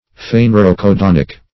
Search Result for " phanerocodonic" : The Collaborative International Dictionary of English v.0.48: Phanerocodonic \Phan`er*o*co*don"ic\, a. [Gr. fanero`s evident + kw`dwn a bell.]
phanerocodonic.mp3